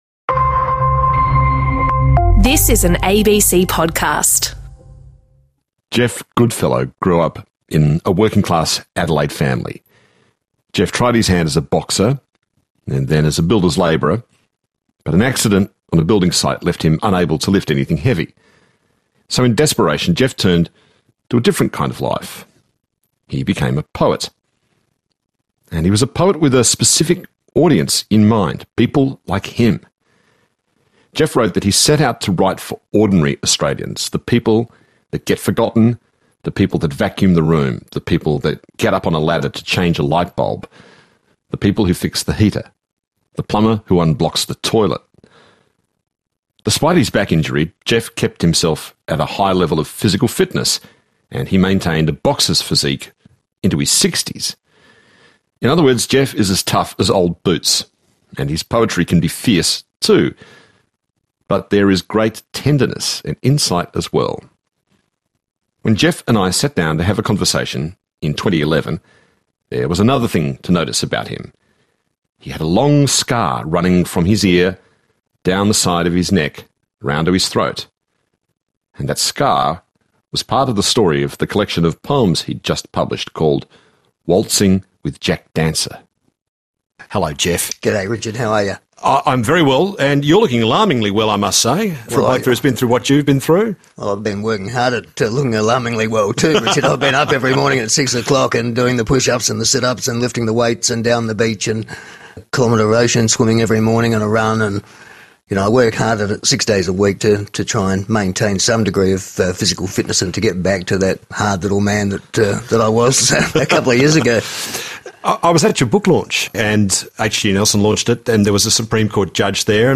Source: ABC Radio Presenter: Richard Fidler